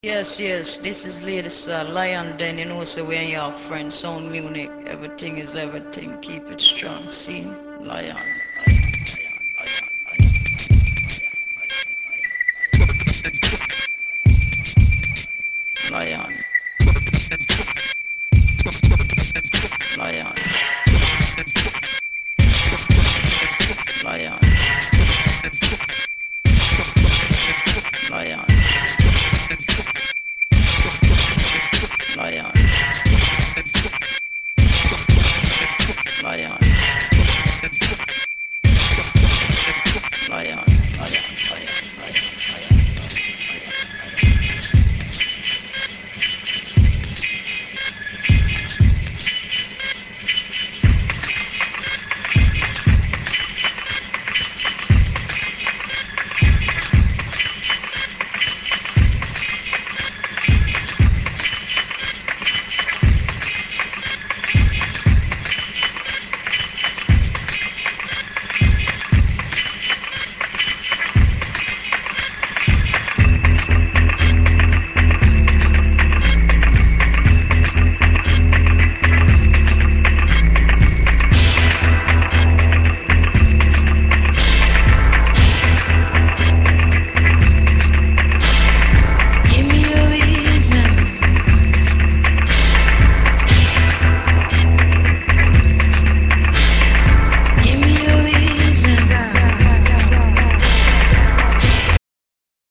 Dub, Downbeat und Drum&Bass